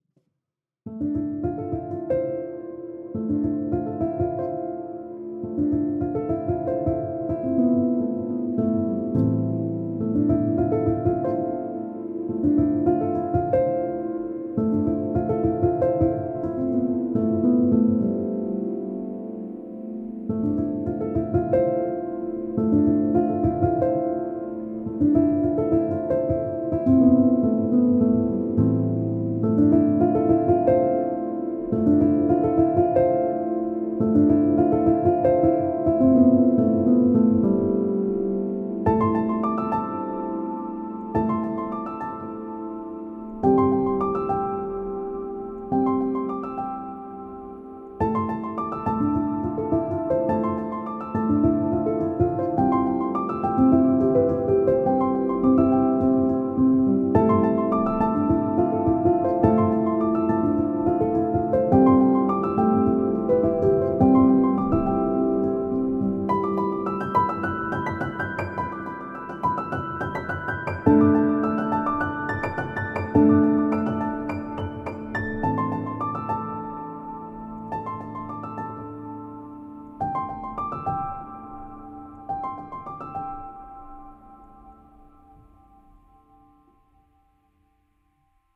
piano music with a peaceful and introspective mood